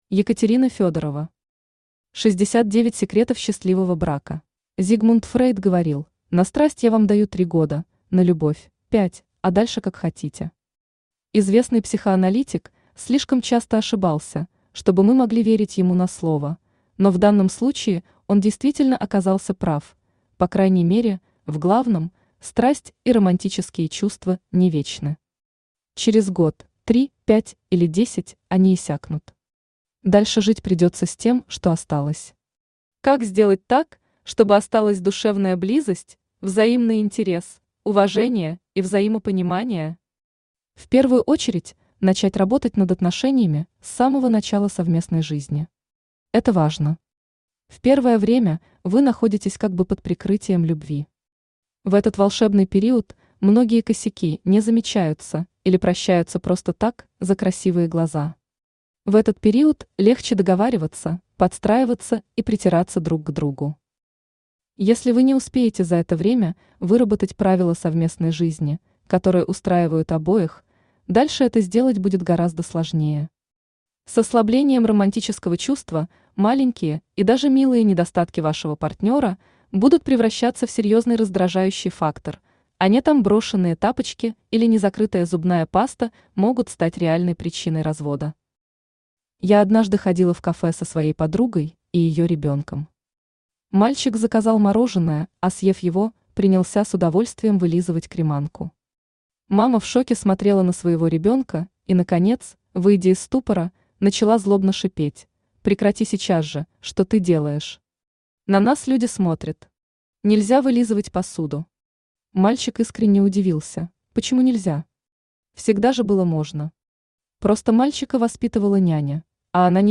Аудиокнига 69 секретов счастливого брака | Библиотека аудиокниг
Aудиокнига 69 секретов счастливого брака Автор Екатерина Викторовна Федорова Читает аудиокнигу Авточтец ЛитРес.